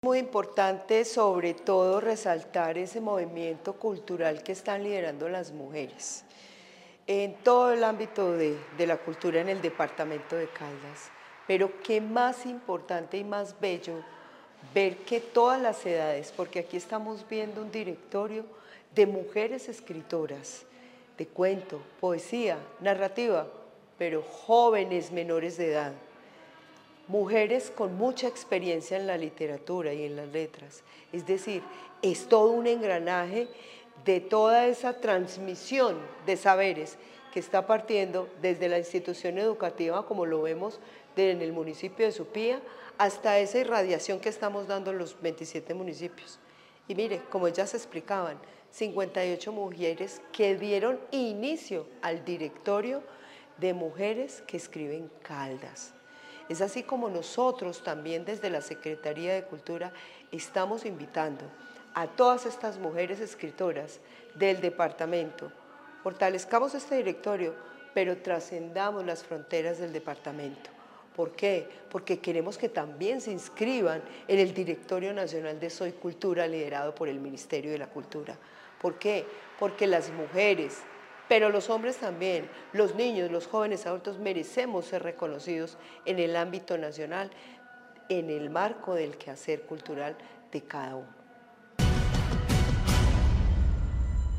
Luz Elena Castaño Rendón, secretaria de Cultura de Caldas